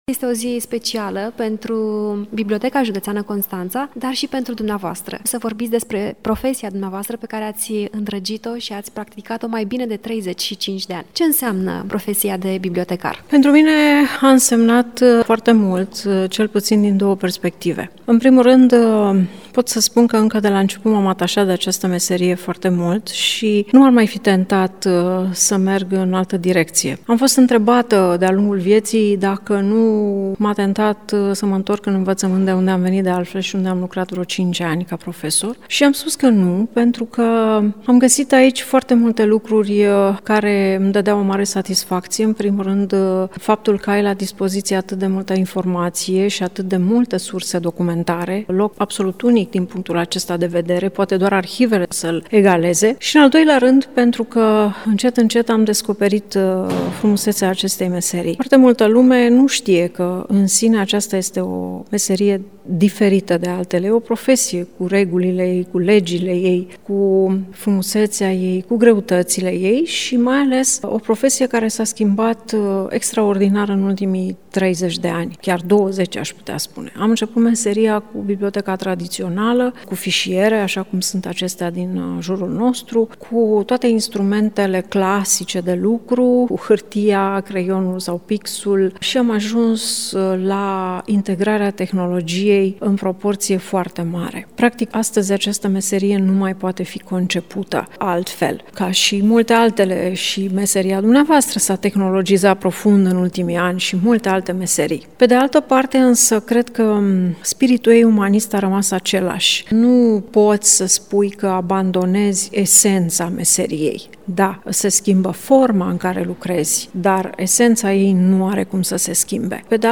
În cadrul unui interviu pentru Radio Constanța